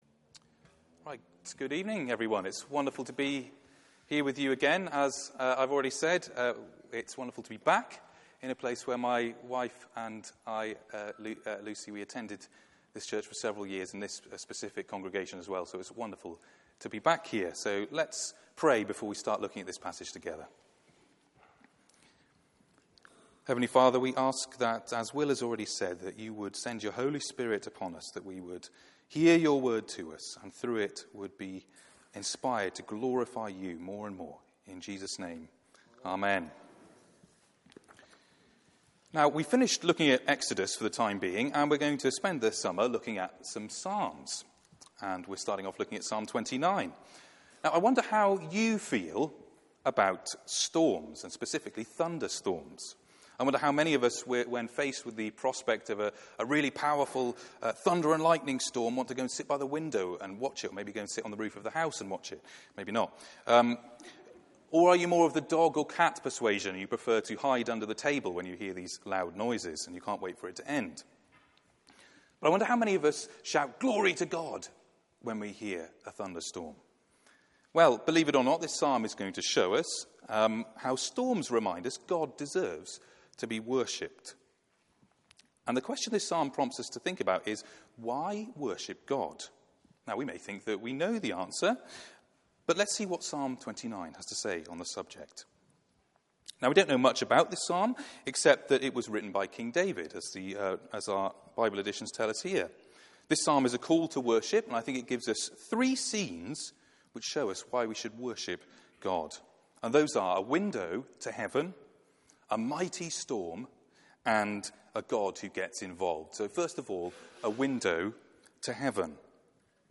Passage: Psalm 29 Service Type: Weekly Service at 4pm